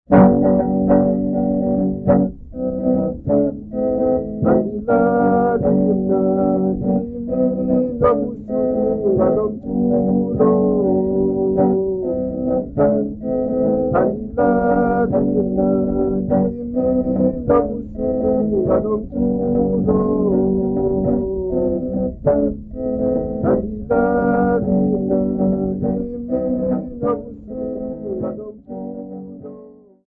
Folk music -- South Africa
Guitar
field recordings
Instrumental music
Topical Xhosa urban song with guitar accompaniment